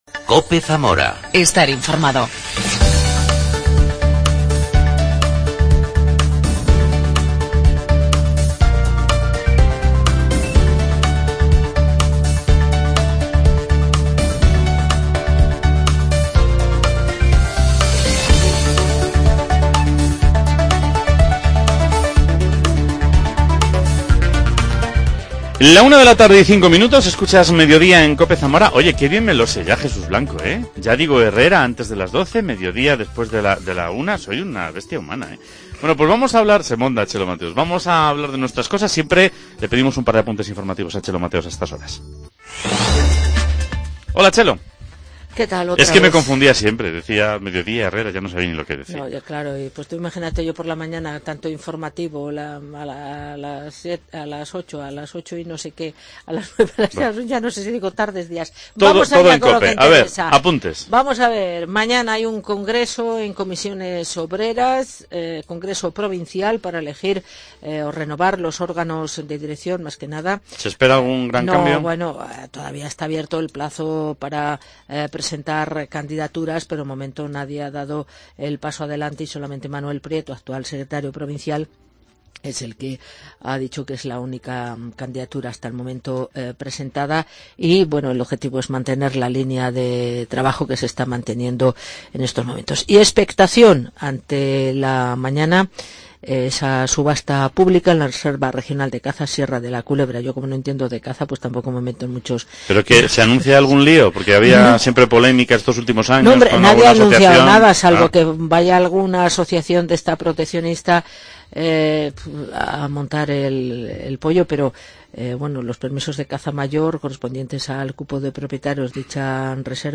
Charla
en la concentración convocada ayer por la Plataforma de Zamora por la Escuela Pública.